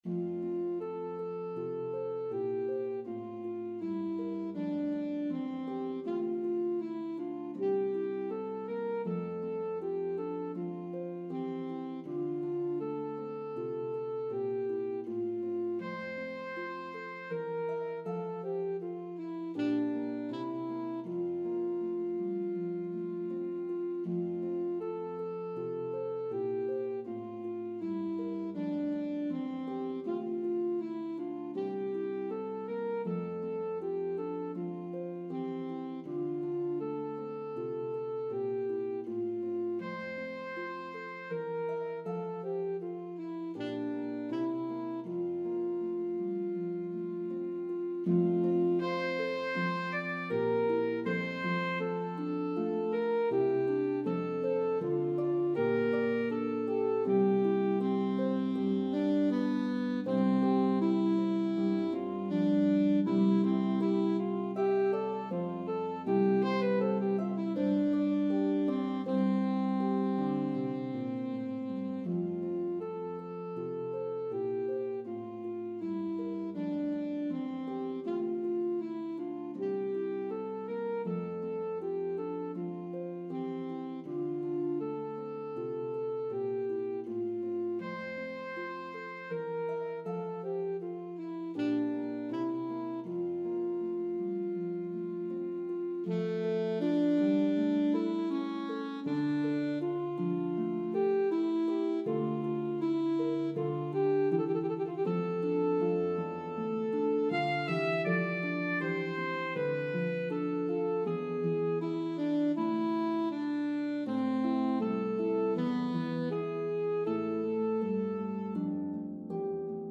This well known Baroque piece